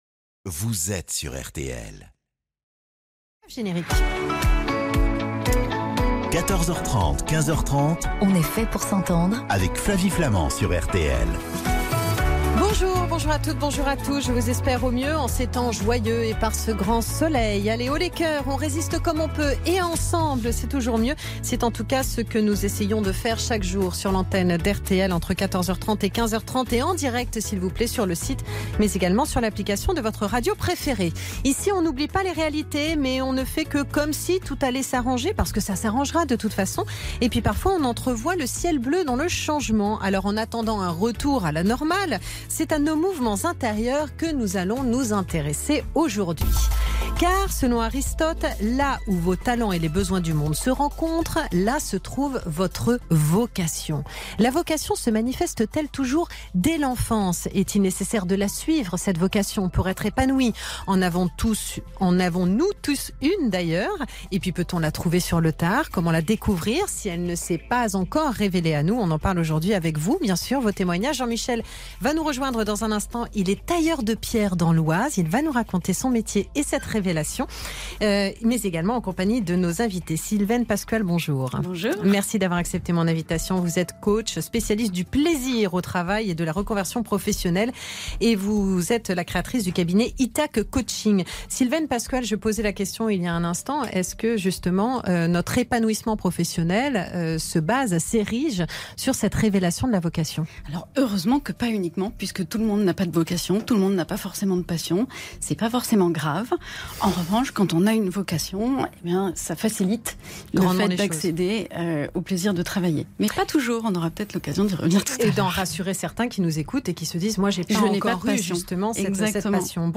J’ai donc participé à l’émission On est fait pour s’entendre, présentée par Flavie Flament mardi 16 mars, sur le thème de la vocation en compagnie de